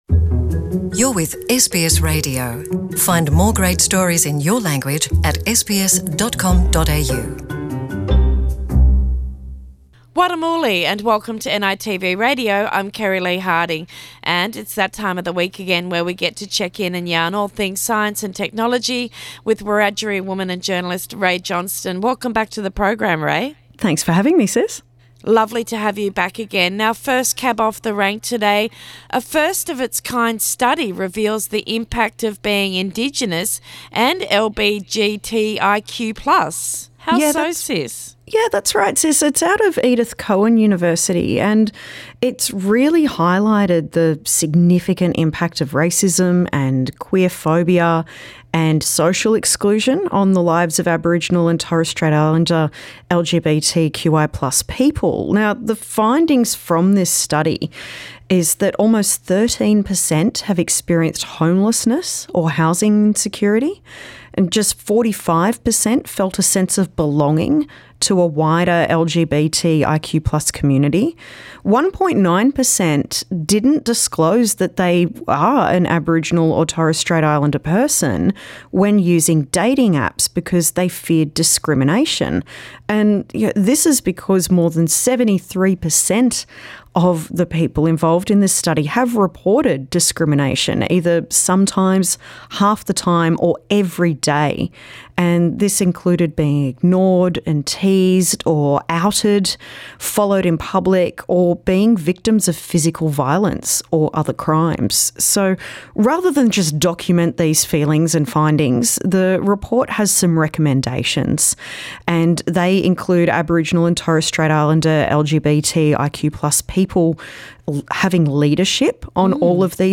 Our regular science & technology segment with Wiradjuri woman and Journalist